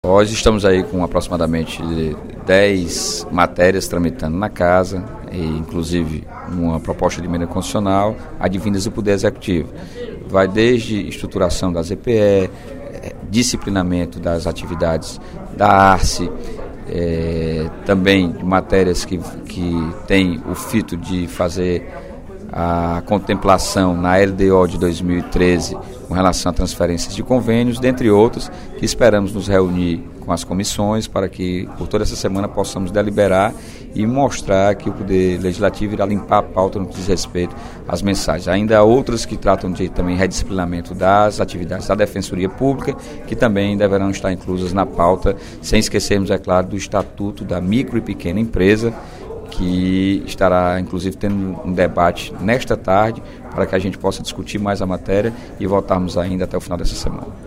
No primeiro expediente da sessão plenária desta terça-feira (18/12), o deputado Sérgio Aguiar (PSB) destacou oito mensagens do Poder Executivo, que estão tramitando na Assembleia.